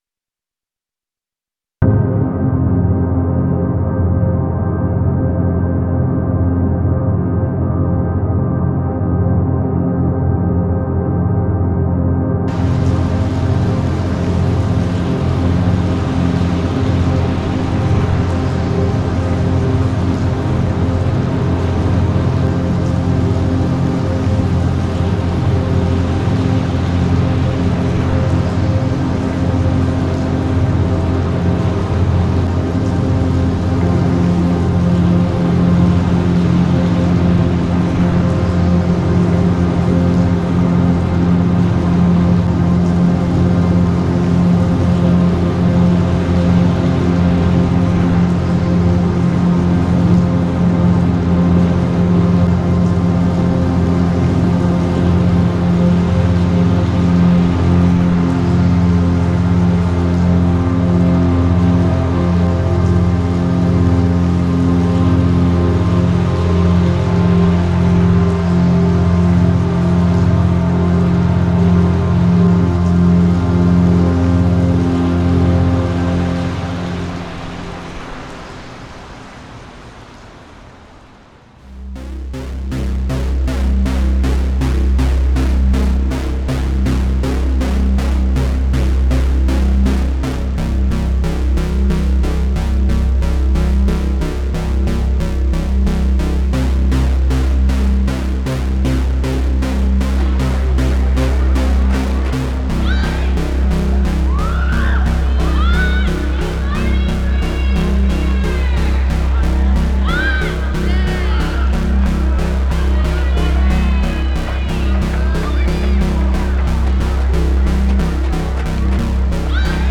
AMBIENT TEXTURES AND FIELD RECORDINGS IN SYMBIOSIS
AMBIENT-LANDSCAPES-DEMO.mp3